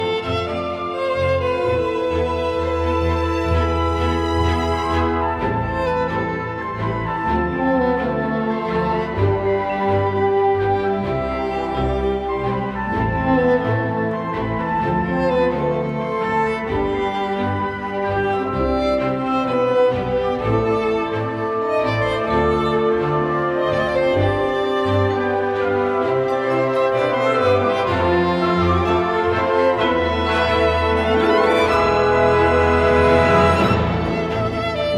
Жанр: Классика
Classical, Violin, Chamber Music, Orchestral